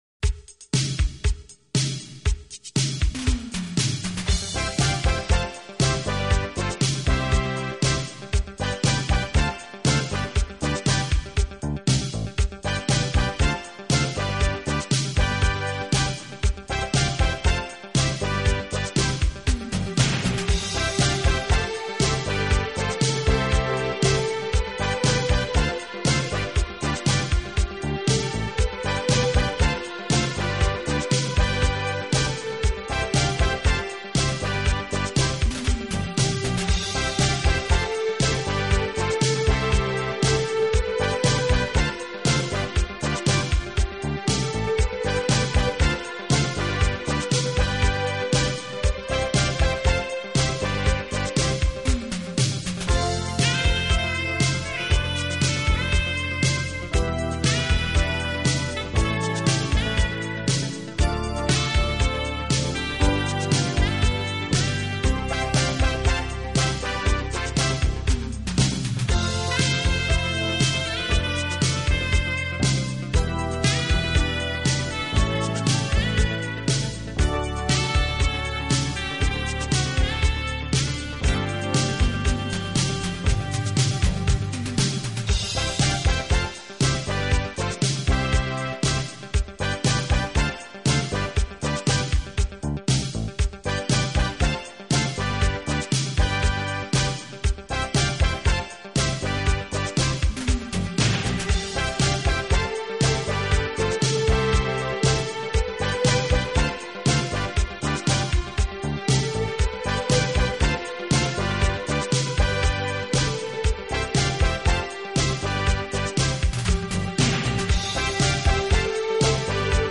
【轻音乐】
感觉整体比较严肃，跳跃的节奏中带着一丝凝重